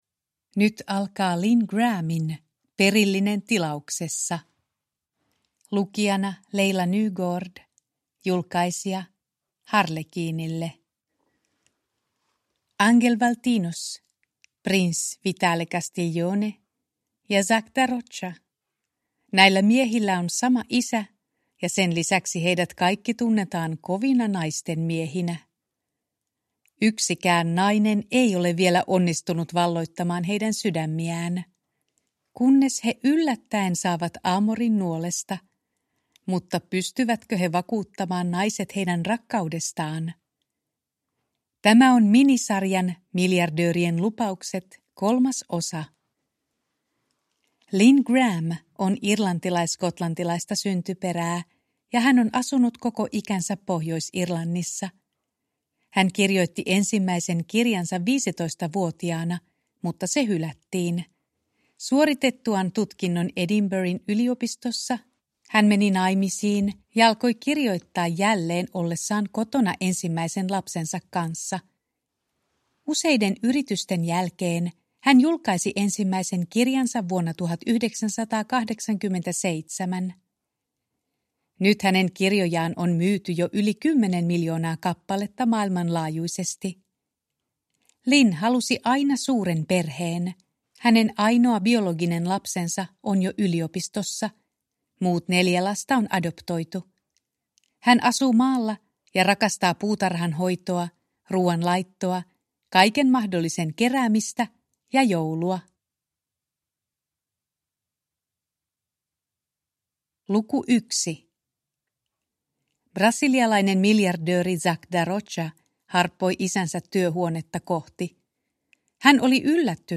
Perillinen tilauksessa – Ljudbok – Laddas ner